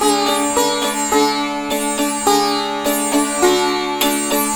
105-SITAR4-R.wav